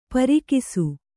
♪ parikisu